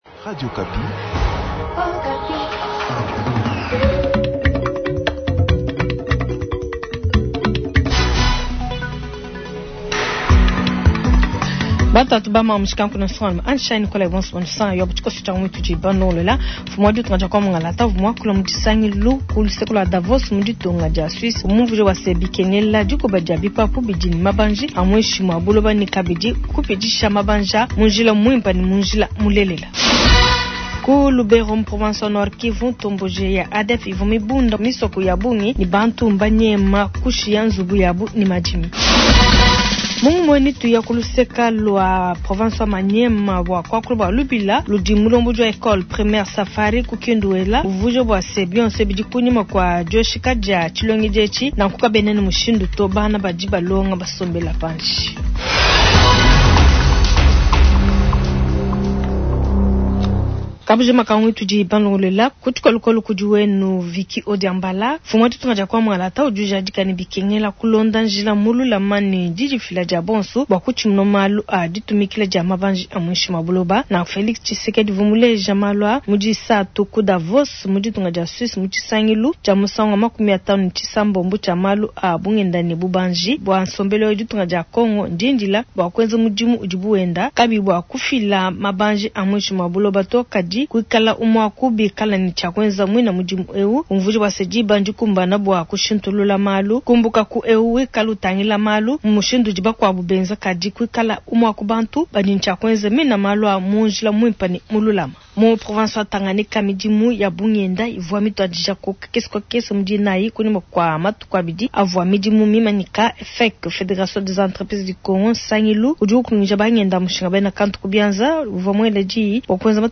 Journal matin